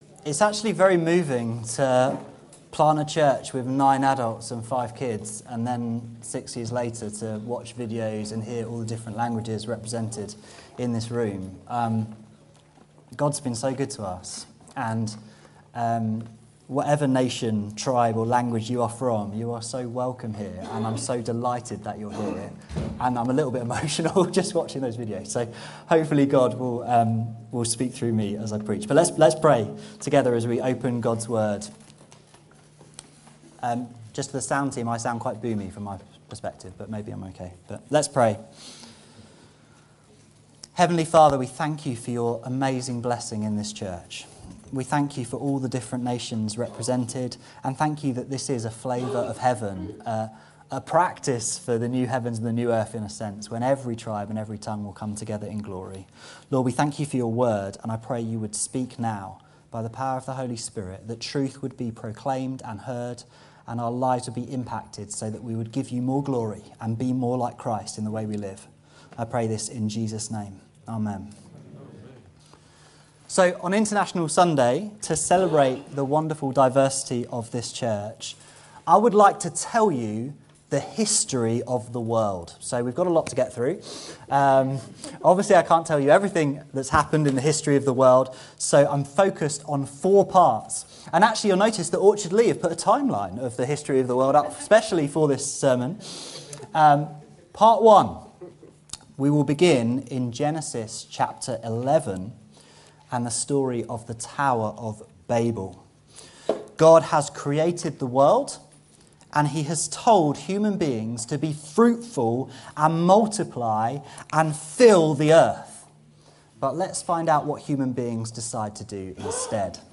This sermon briefly covers the History of the World, taking in the Tower of Babel, the Day of Pentecost, Pauls challenge to Peter on disunity in the book of Galatians, and ends with a beautiful vision of united worship described in the book of Revelation.